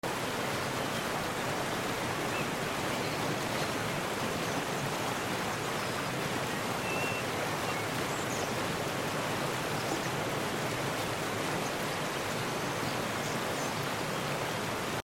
Gliding backwards down a crystal-clear creek with hues of blue, the water rushes past mossy rocks glowing in the sunlight, weaving around fallen trees that stretch across the forest stream. The mix of sunlight, shadows, and flowing water creates a peaceful and timeless Pacific Northwest moment 😌 Gliding Backwards Down A Crystal Clear Sound Effects Free Download.